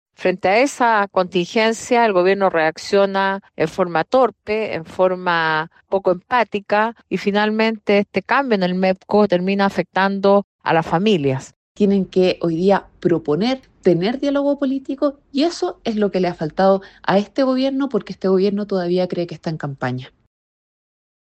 Así lo expresaron la presidenta del Partido Socialista, Paulina Vodanovic, y la jefa de bancada del Partido Comunista, Daniela Serrano.